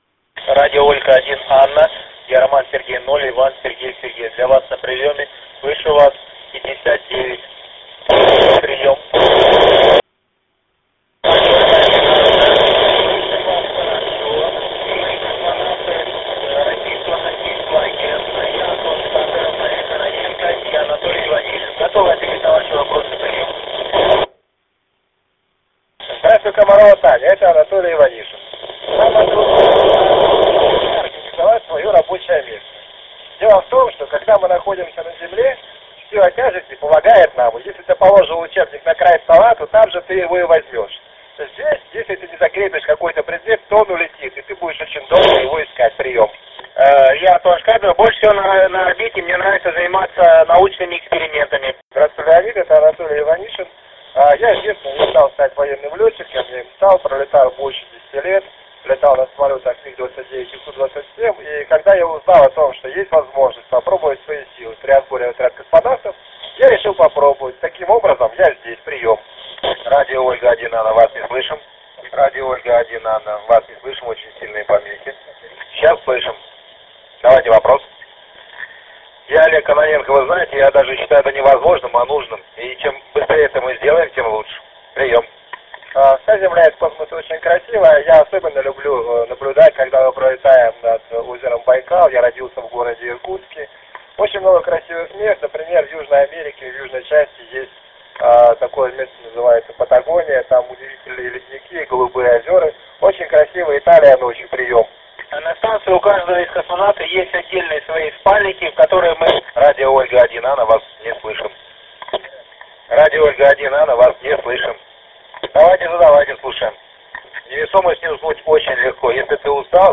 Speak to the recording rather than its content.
2012-04-12 20:31 UTC Russian ISS crew members - contact with RO1A QSO in russian language heard using base vertical omnidirectional antenna. Report was up to 55. Good receive till east horizon. 145.800; Ant: vertical, omnidirectional; TRX: FT-7800; Loc: KO02JD